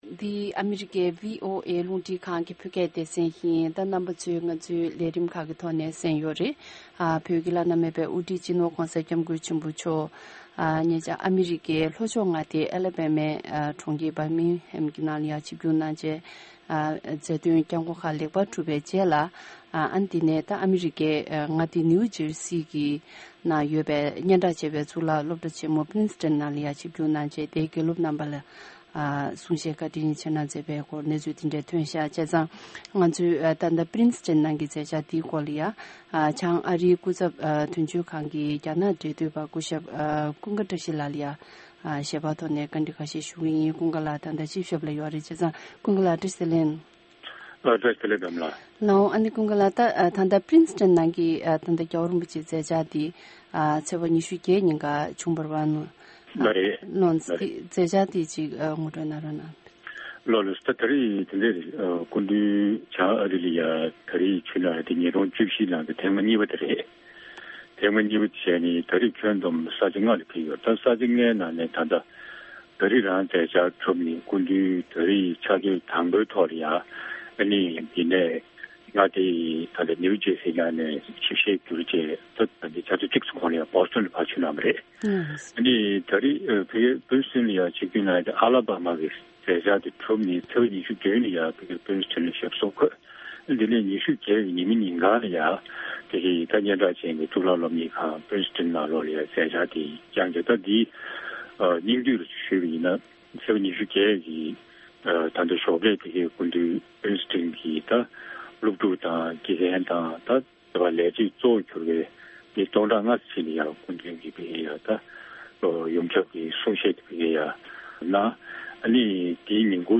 ༧གོང་ས་མཆོག་གིས་ཨ་རིའི་པི་རིན་སི་ཏྲོན་གཙུག་ལག་སློབ་གཉེར་ཁང་ལ་གསུང་བཤད་གནང་བ།
ཨ་མི་རི་ཀའི་མངའ་སྡེ་ནིའུ་ཇར་སིའི་ནང་ཡོད་པའི་སྙན་གྲགས་ཆེ་བའི་Princetonཔི་རིན་སི་ཏྲན་གཙུག་ལག་སློབ་གྲྭ་ཆེ་མོའི་ནང་། དགེ་སློབ་ལྔ་སྟོང་མིན་ཙམ་ཞིག་ལ། ནང་སེམས་ལ་འགྱུར་བཅོས་བྱས་ཏེ་ བྱམས་པ་དང་སྙིང་རྗེ་ལ་སོགས་པའི་མིའི་བཟང་པོའི་ཡོན་ཏན་དེ་རྣམས་རང་རྒྱུད་ལ་སྐྱེ་ཐབས་བྱ་རྒྱུ་གལ་ཆེ་ཡིན་པའི་ཐད། གསུང་བཤད་བཀྲིན་ཆེ་གནང་མཛད་ཡོད་ཅིང་།